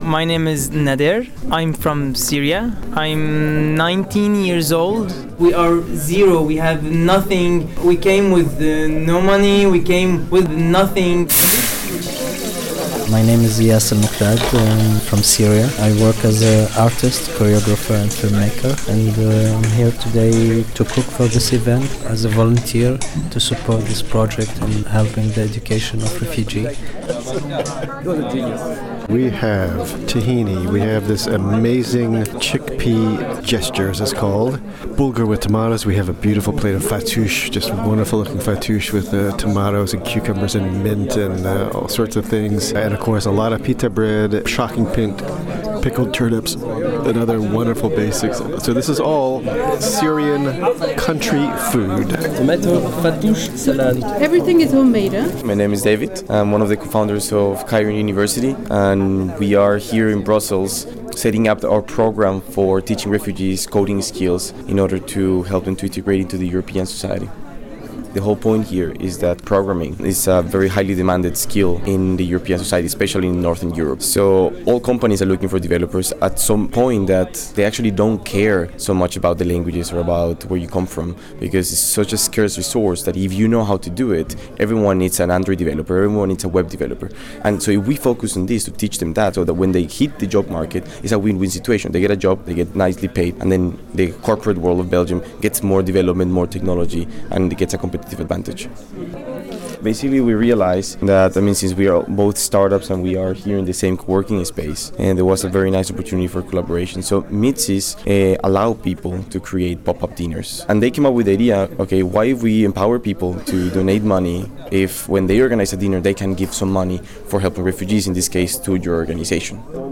some of the organisers behind the scheme